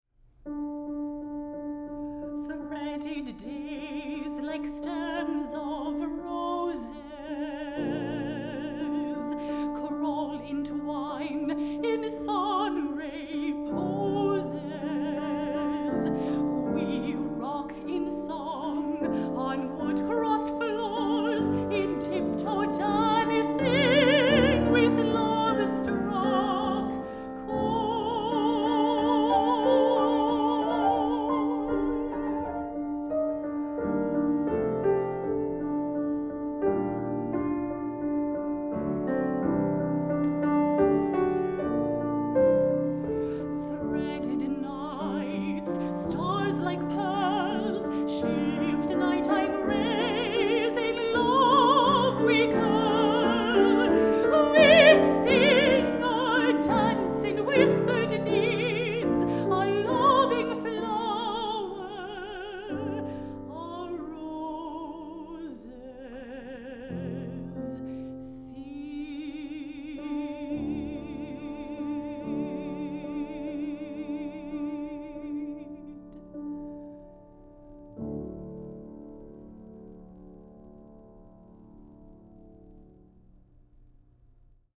Instrumentation: mezzo soprano, piano